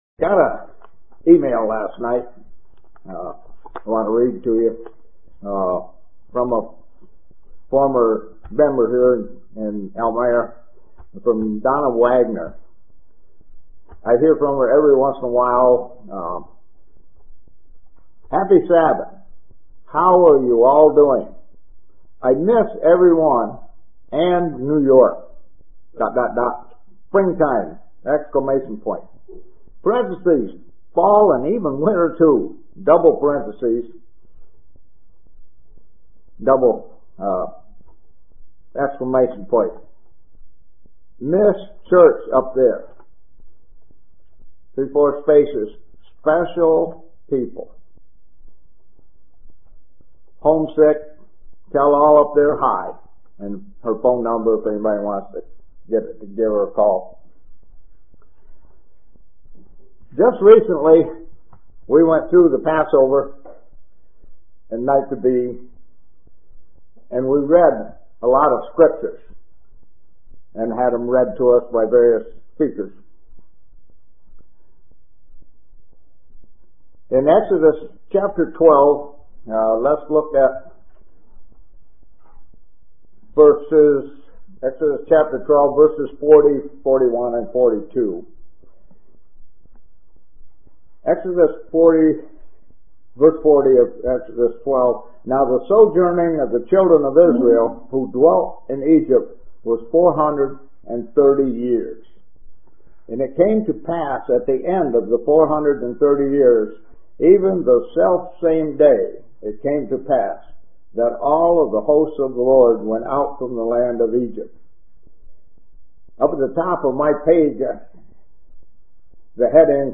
Print The same day years later UCG Sermon Studying the bible?